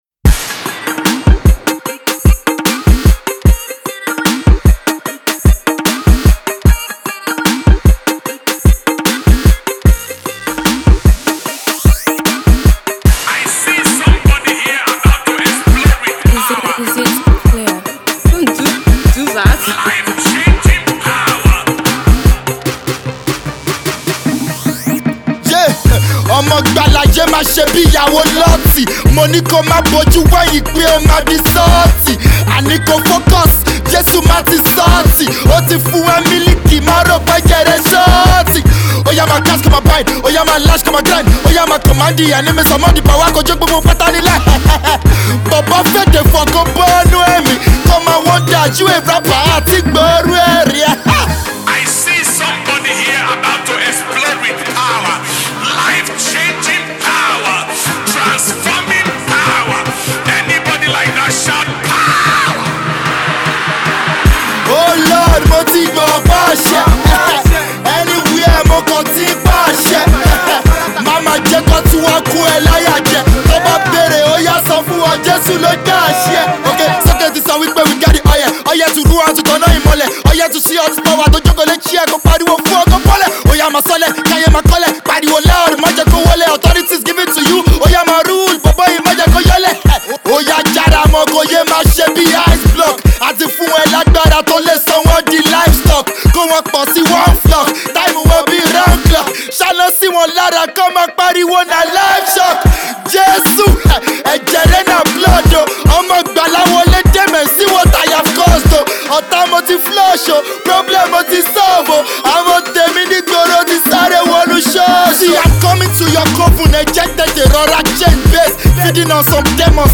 authoritative song
with a powerful delivery